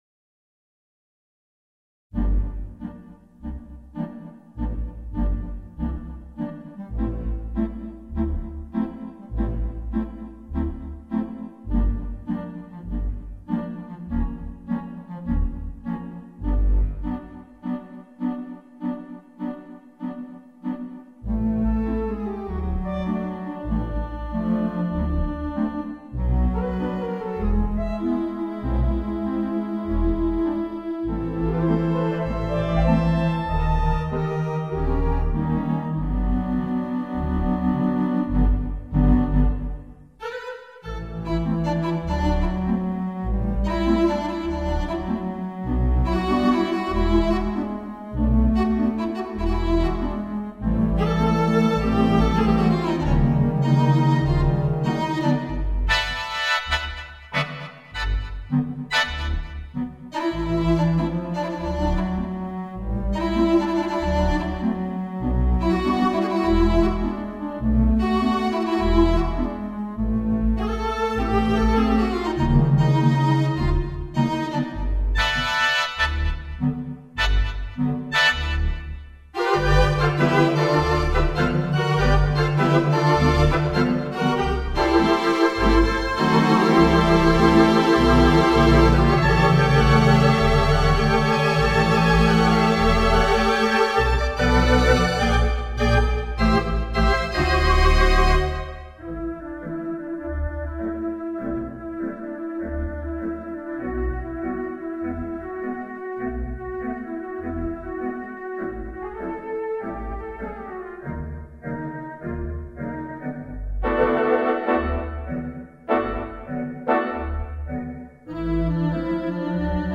Virtual Orchestral Organ
4/54 Symphonic VI Virtual Orchestral Organ.